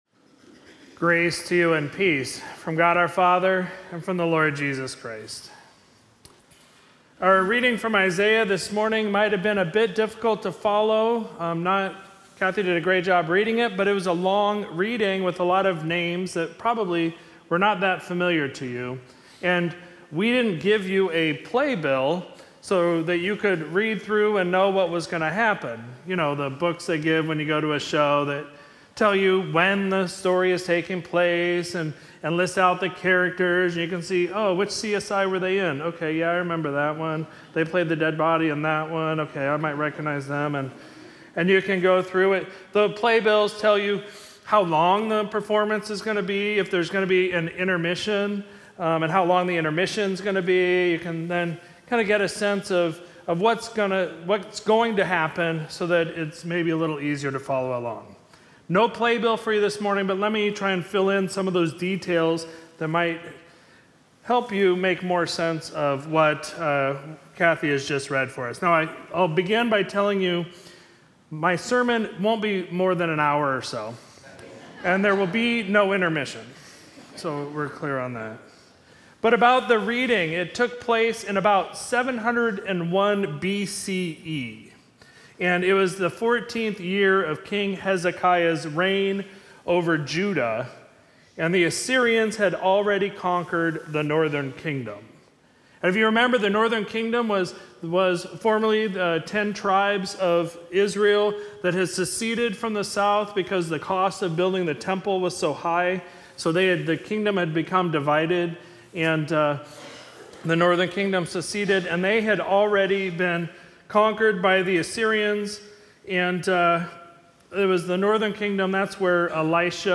Sermon for Sunday, November 20, 2022